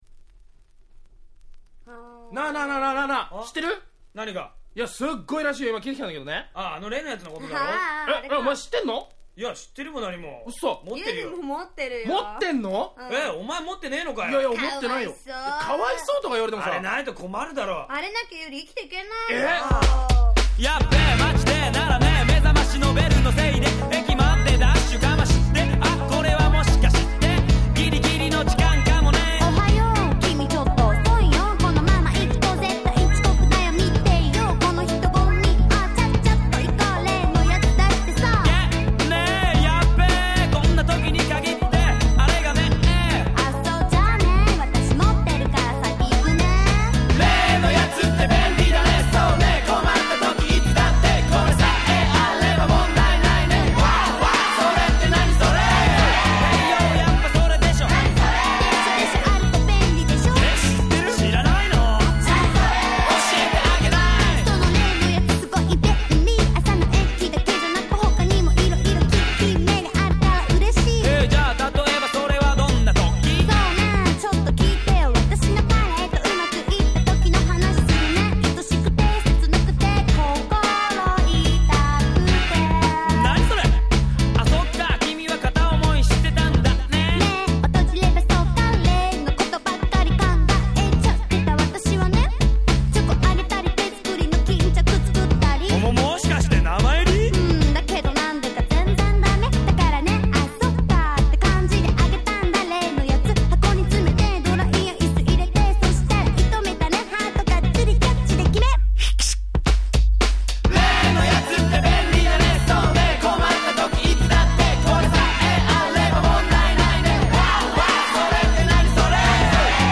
95' J-Rap Classic !!